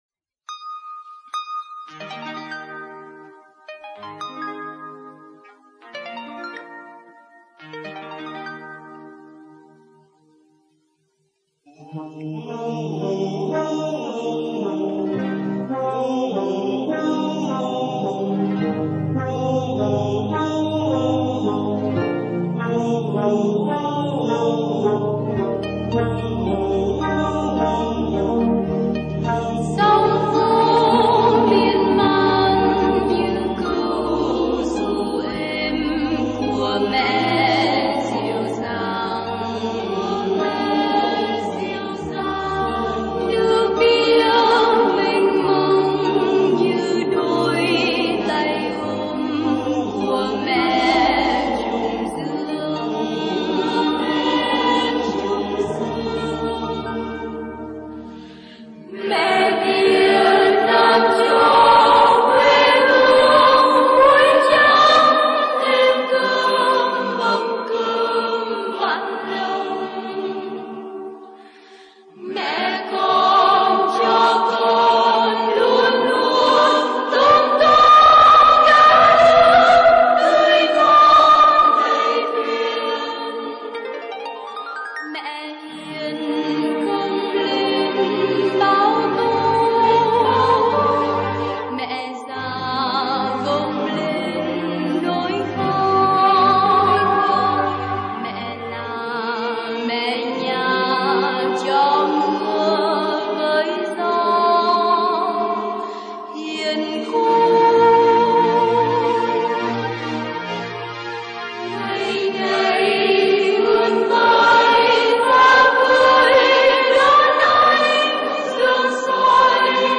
hợp xướng